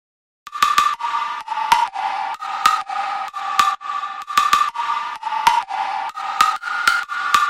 家庭主音合成器
描述：一个简单的家庭合成器。
Tag: 128 bpm House Loops Synth Loops 1.26 MB wav Key : C